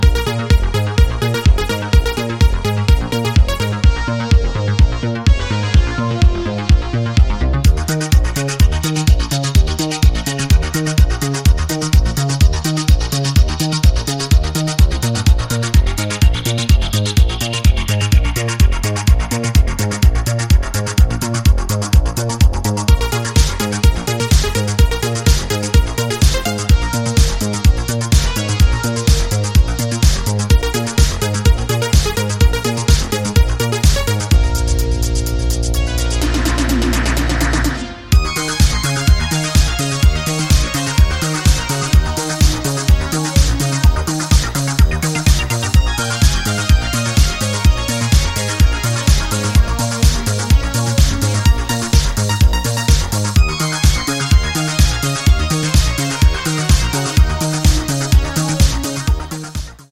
コミカルな80sサウンドをモダンなセンスで昇華した、ナイスな1枚です！